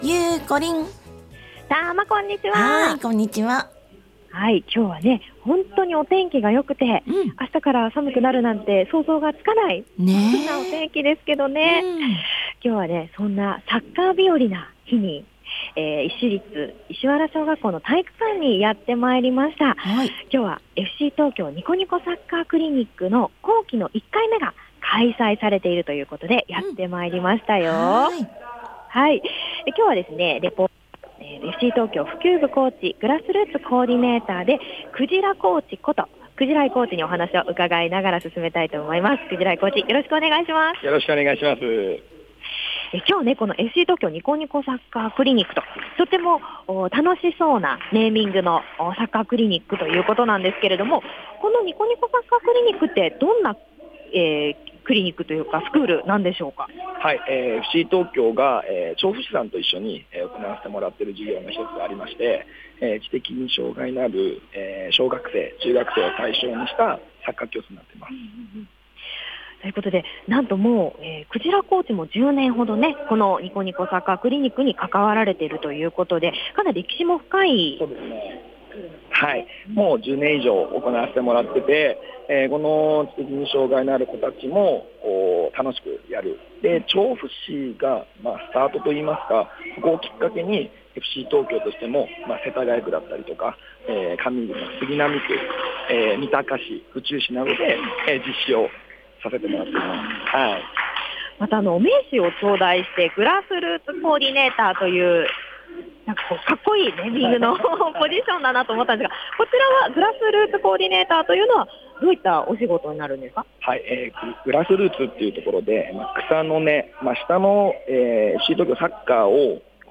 そんな今日はFC東京にこにこサッカークリニックに行ってきました！